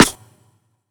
FSY_RIM.wav